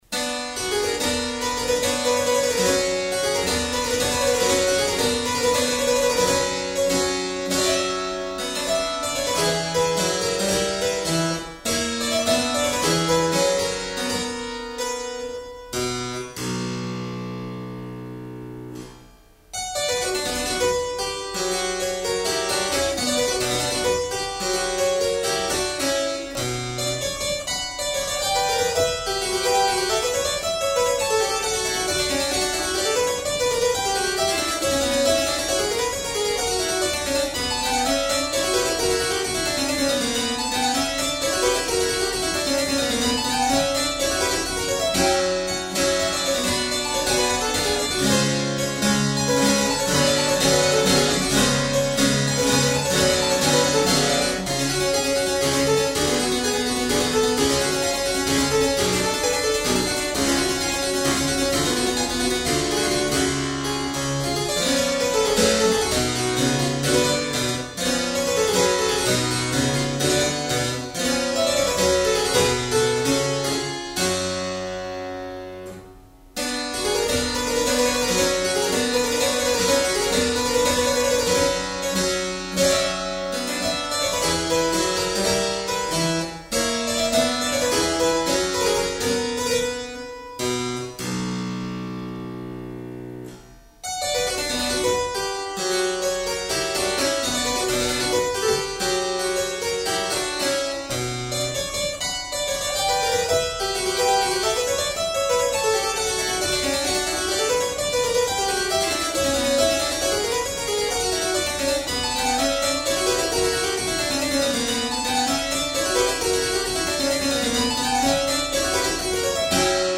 clavicembalo